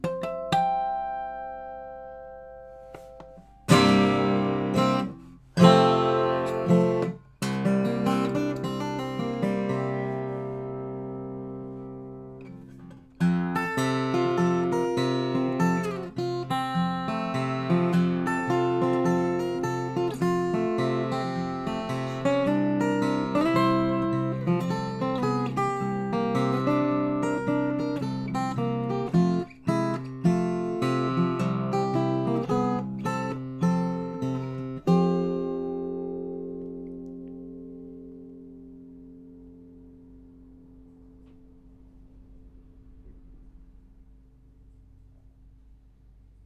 For a reference I included a Rode NT4 stereo mic connected to the Echo Audiofire Pre8 here at Digital Duck studios (my upstairs man-cave).
When you go to the fadeout and turn up the volume, you should be able to hear a car go by out on the street, even though the window was closed.
Even if I had the extra cash for the Sony, while I like its lower noise floor, I think it sounds a bit lifeless compared to the h2n (and the gorgeous sounding Rode) in this test.